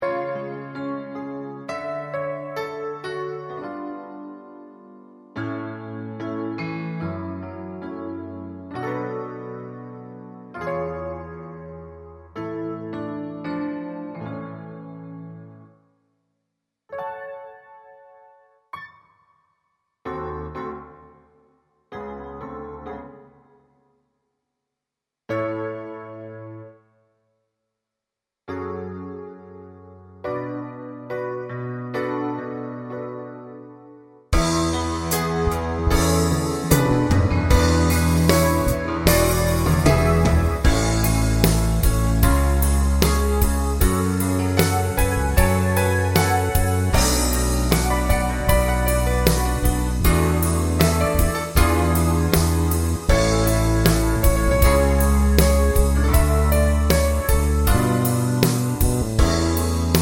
Live Version with No Backing Vocals Crooners 4:10 Buy £1.50